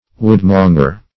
Woodmonger \Wood"mon`ger\, n.